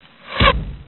FIGHT-Arrow+Swoosh
Tags: combat